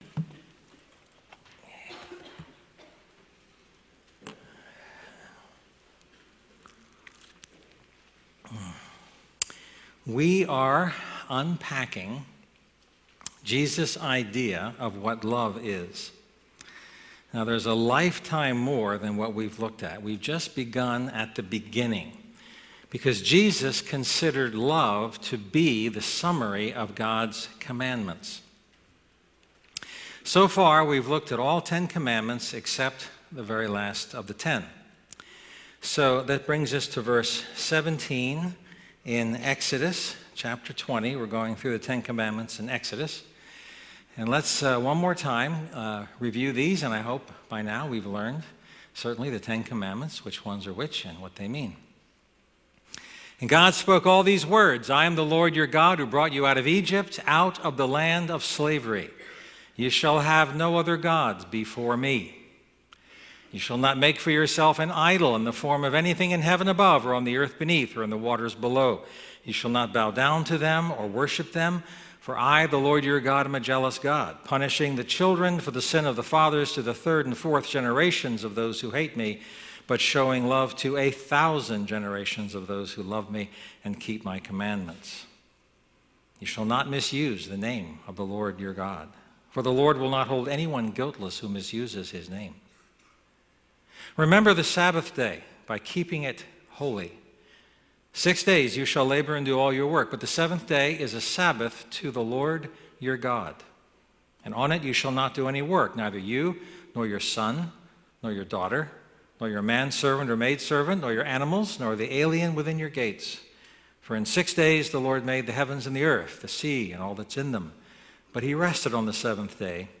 A message from the series "Love Unpacked."